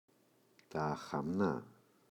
αχαμνά, τα [axa’mna]